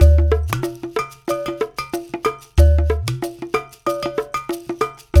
93 -UDU 00L.wav